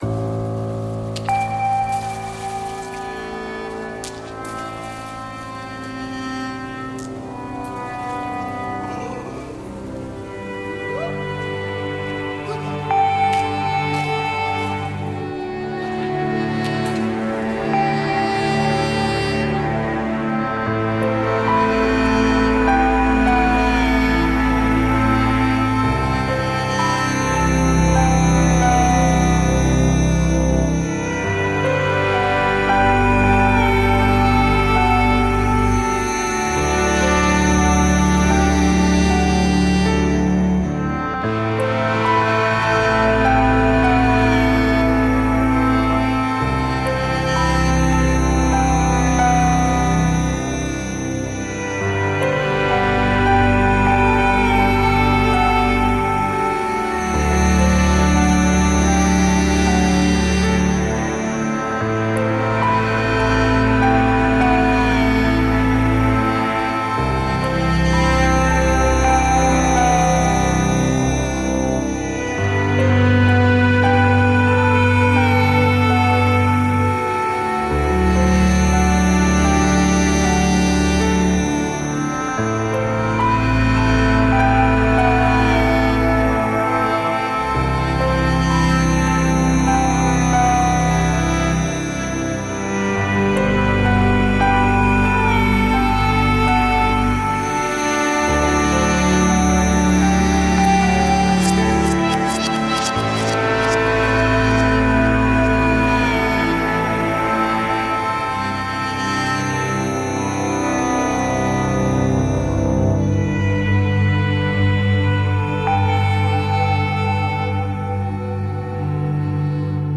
Mesmerizing cinematic compositions with a touch of rock.